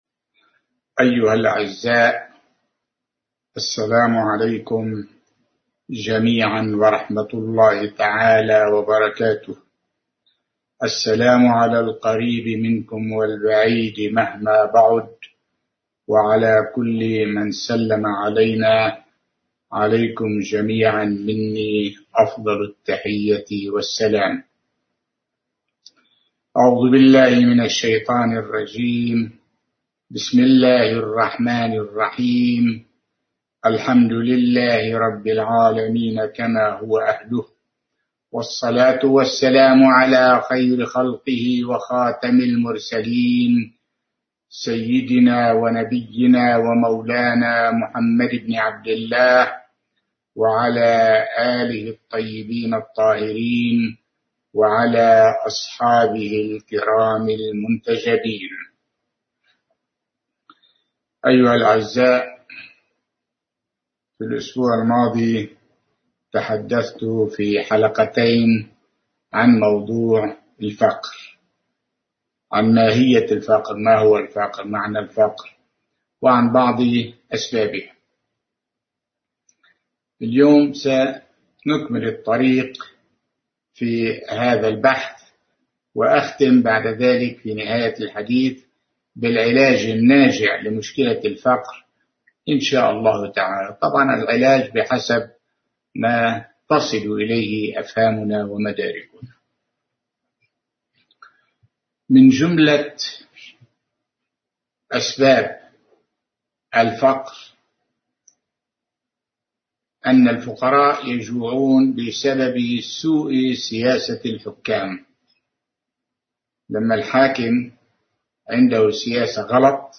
الفقر أسبابه وعلاجه (المحاضرة الثالثة) – مركز النور الإسلامي للعلوم والمعارف